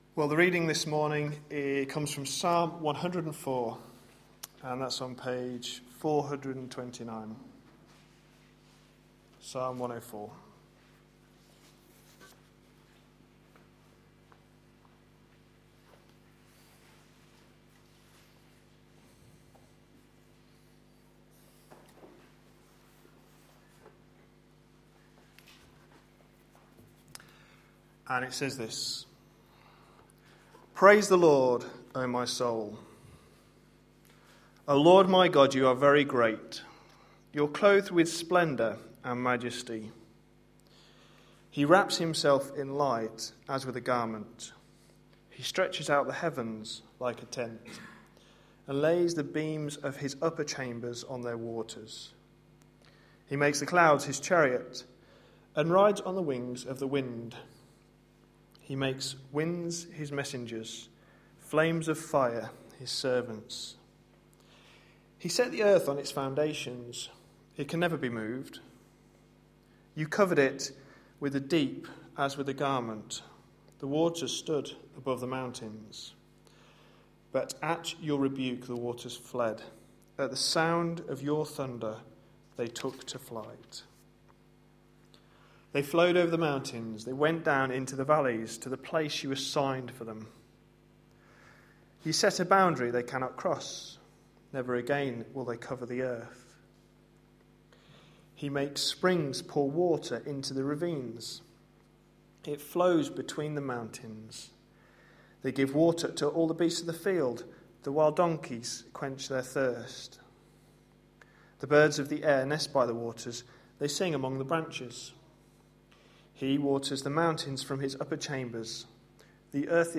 A sermon preached on 27th January, 2013, as part of our The gospel is the reason series.